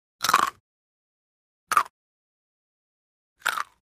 Звуки еды
Кусание и хруст еды